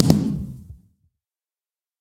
large_blast.ogg